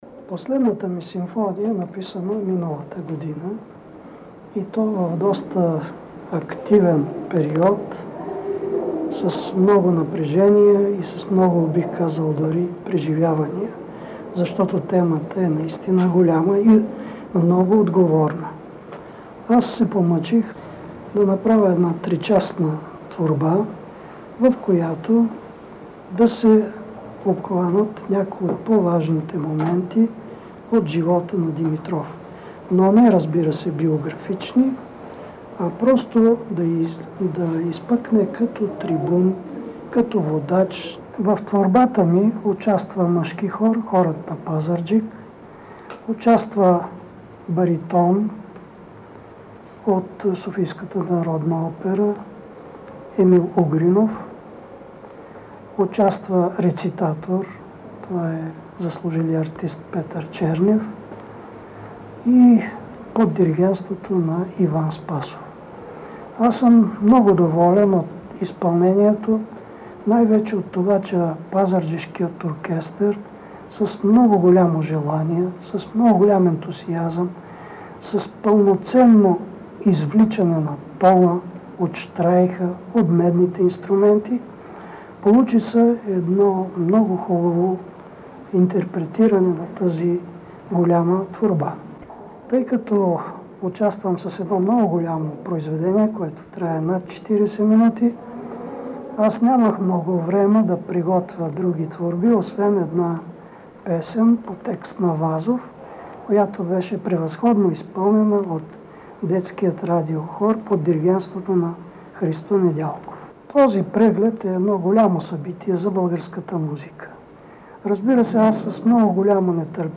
Пример за това е неговата 5-а симфония, за която в интервю от 1982 г. той разказва: